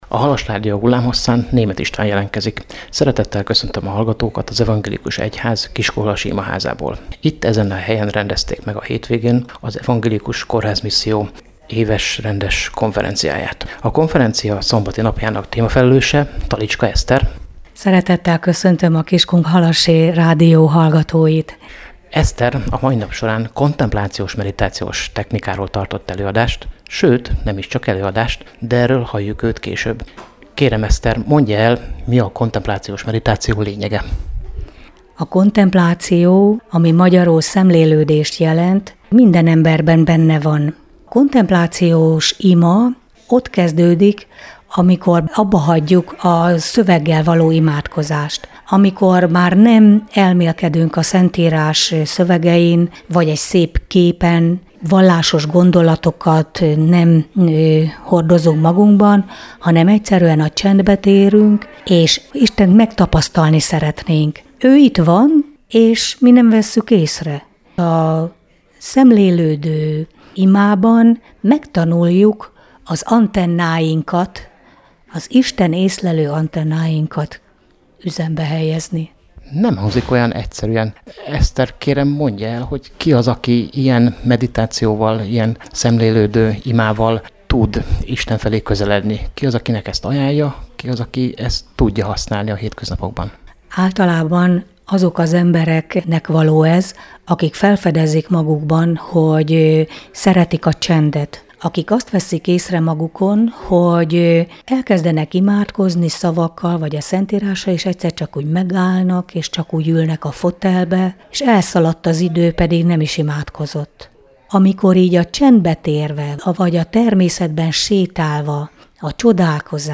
Kiskunhalasi_radio_riport.MP3